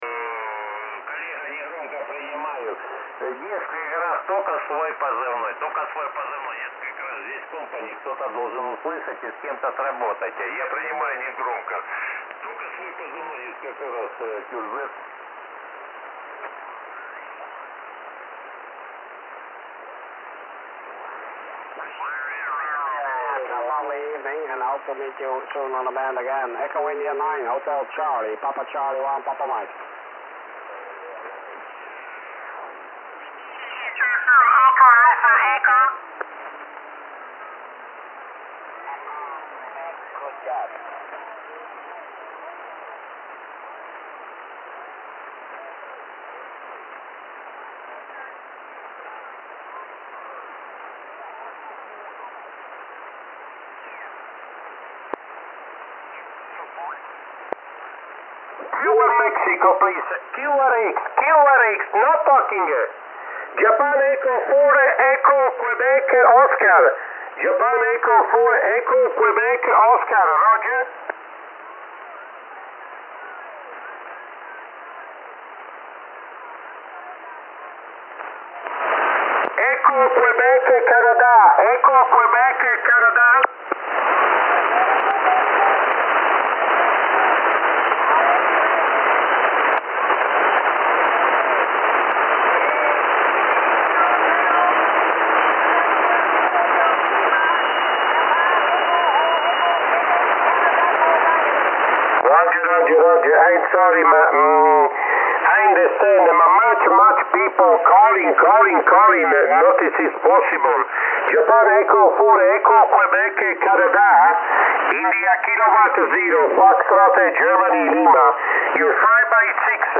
Сделал небольшие записи эфира на 7 МГц, правда проход не очень и помех что то много у меня сегодня.
Следующая запись SSb. Сначала RF=0, потом увеличивал уровень до пяти. Где то на 2:25 нашел тональник, включал NF.
SSB7mhz.mp3